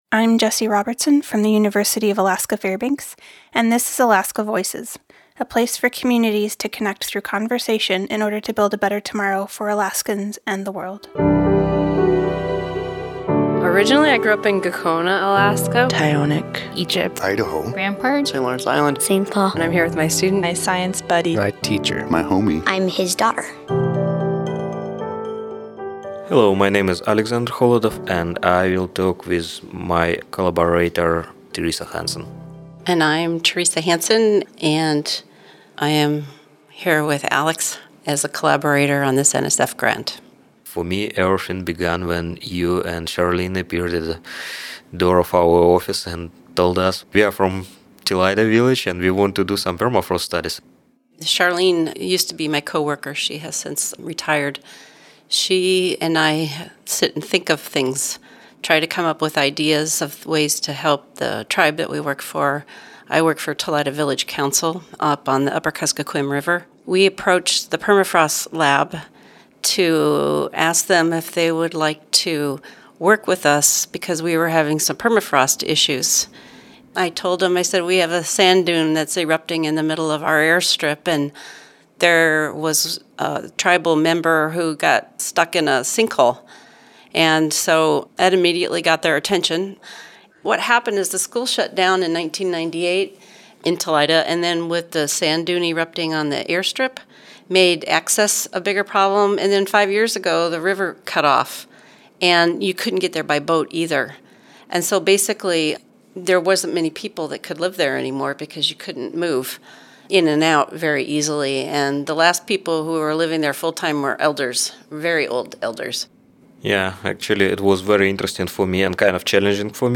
This interview was recorded in collaboration with StoryCorps.